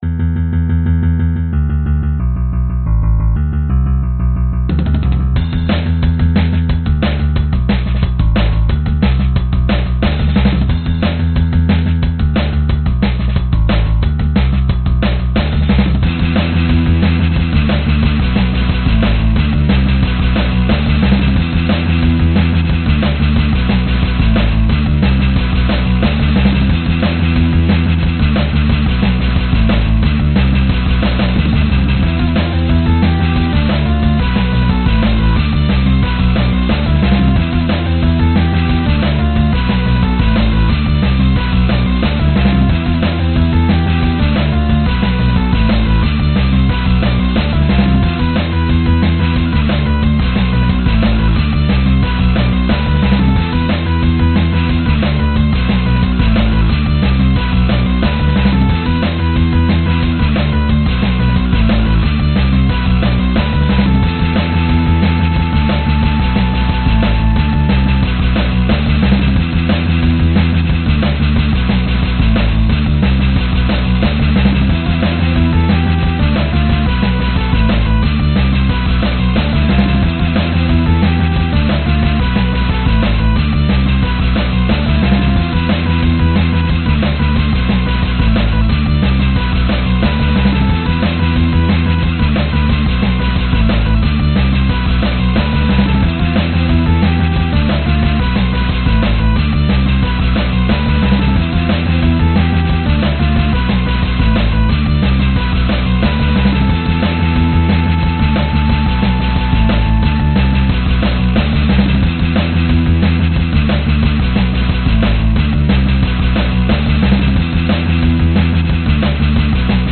描述：用一些一次性电吉他样本进行实验，以创造一个真实的吉他riff。 这种声音无法与真正的吉他相提并论。
Tag: 节日 冬天 朋克摇滚 朋克 吉他 摇滚 贝斯 循环 ATR